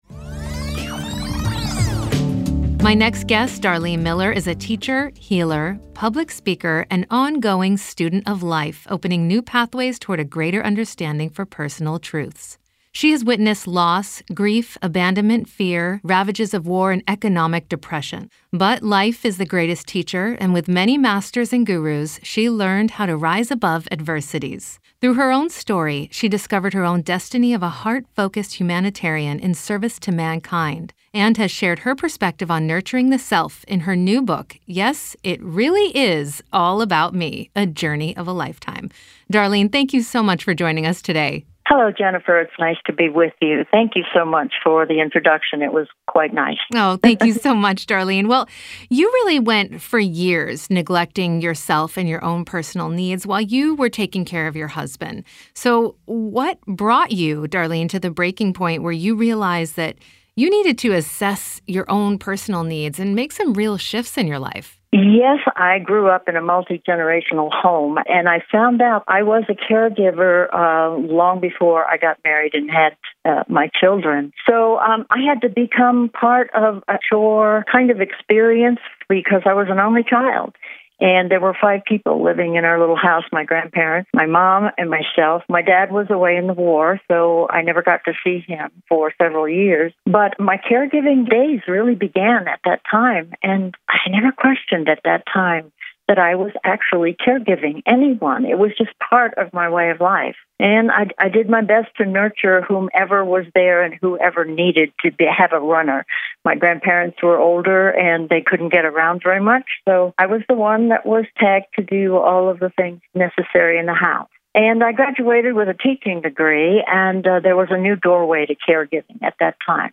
LIVE RADIO INTERVIEW